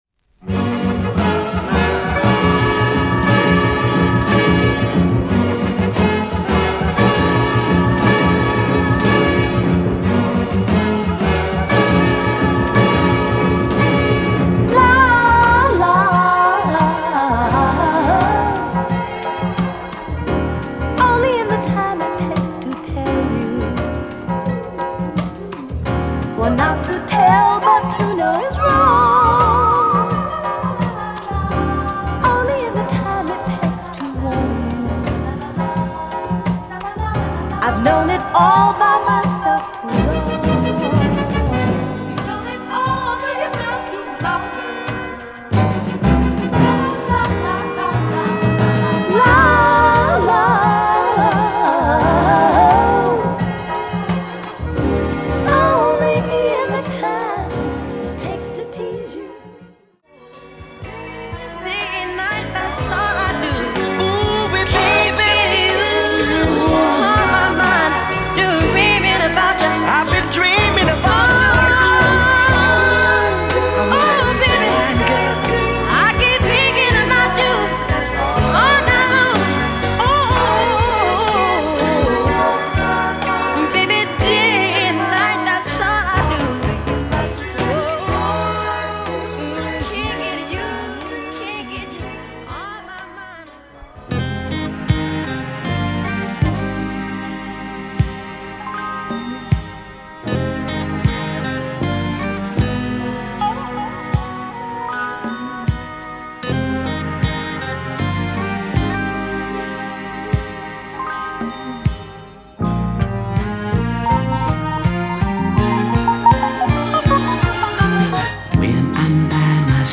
| ヒップホップのサンプリングネタ、定番Soulを中心にマイナー曲もバランスよく収録した極上のSoul Mix！
＊試聴はダイジェストです。
Mellow Groove